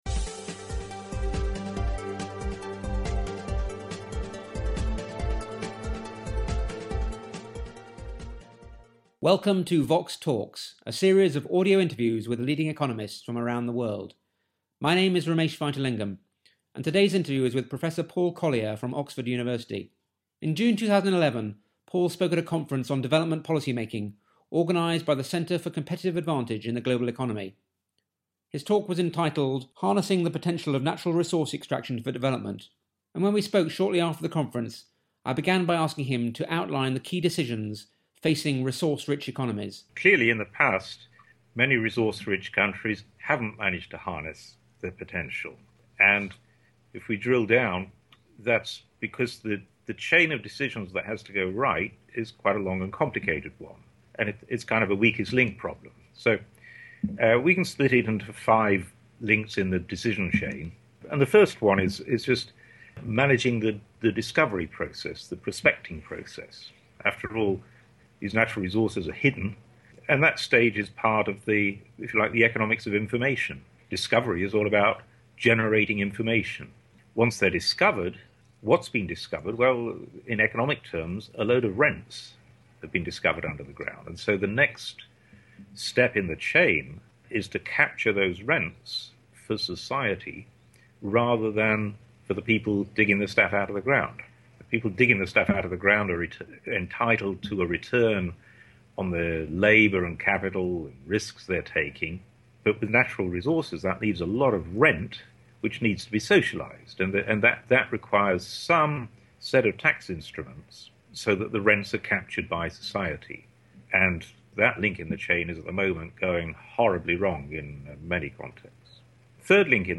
The interview was recorded in June 2011 after a ‘blue-sky’ conference on development policy-making organised by CAGE, the Centre for Competitive Advantage in the Global Economy at the University of Warwick.